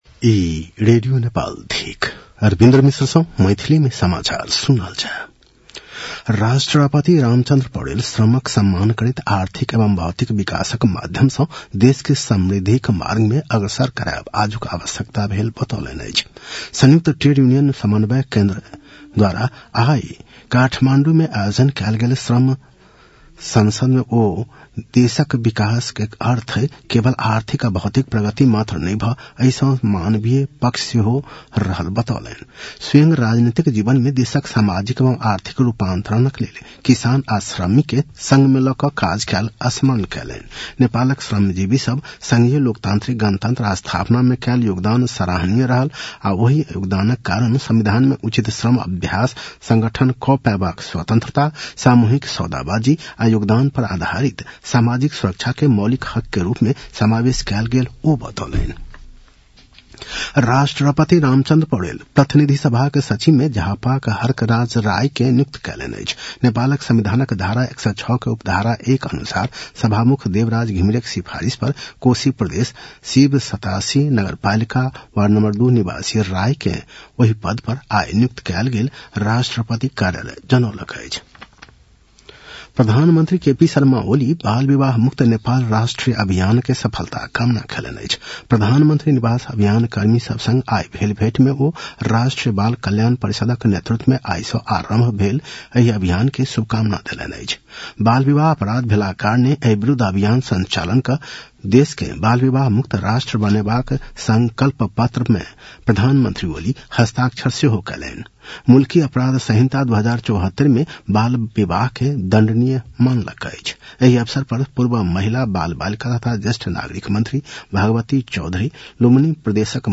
An online outlet of Nepal's national radio broadcaster
मैथिली भाषामा समाचार : १७ पुष , २०८१